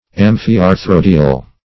Search Result for " amphiarthrodial" : The Collaborative International Dictionary of English v.0.48: Amphiarthrodial \Am`phi*ar*thro"di*al\, a. [Pref. amphi- + arthrodial.]
amphiarthrodial.mp3